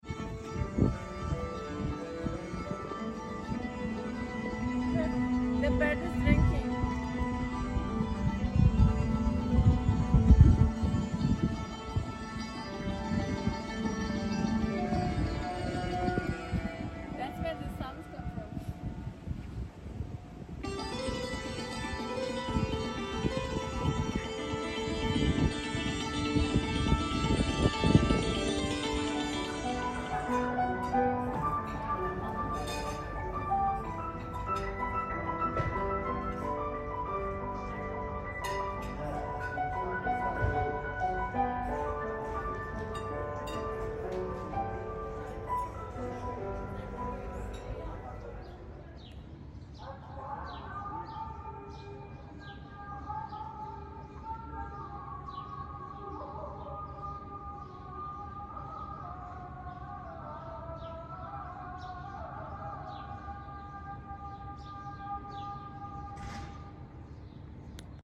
The sights and sound of Isfahan, Iran